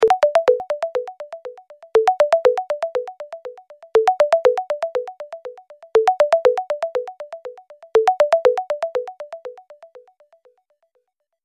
دانلود آهنگ تلفن 4 از افکت صوتی اشیاء
جلوه های صوتی
دانلود صدای تلفن 4 از ساعد نیوز با لینک مستقیم و کیفیت بالا